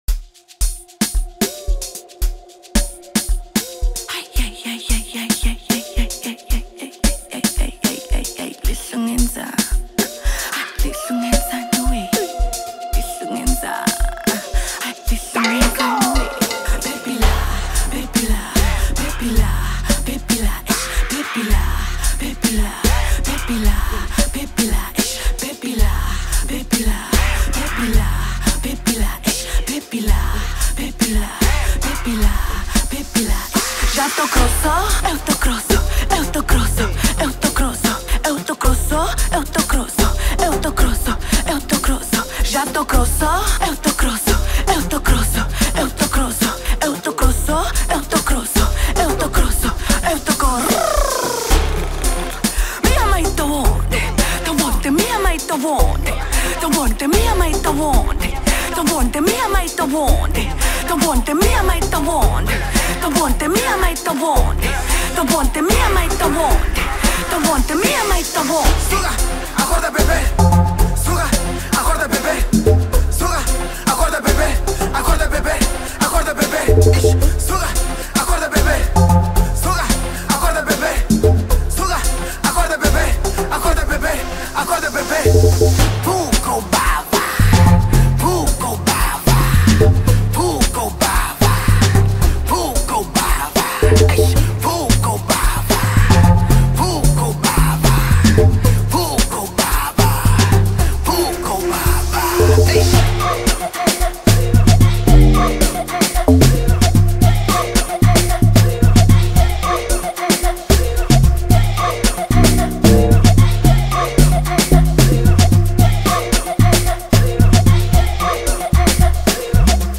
numa vibe amapiano